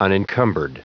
Prononciation du mot unencumbered en anglais (fichier audio)
Prononciation du mot : unencumbered